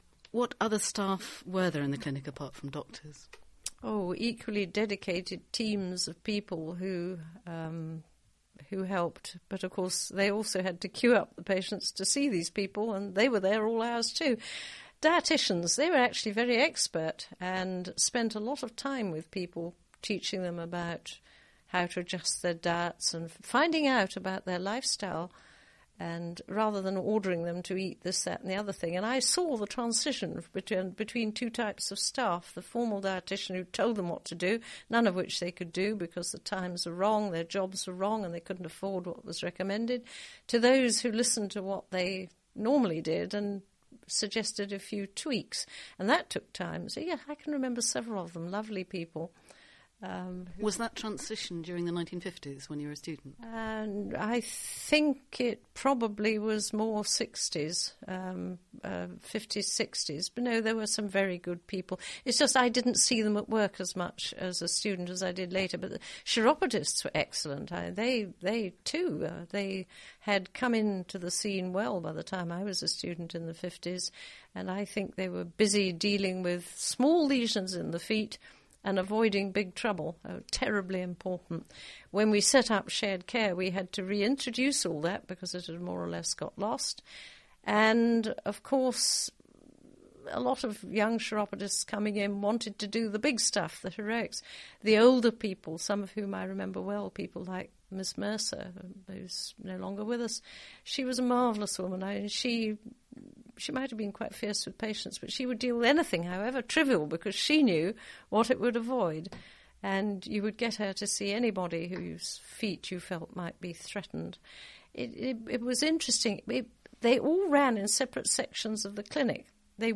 Here you can read through the whole transcript for this interview.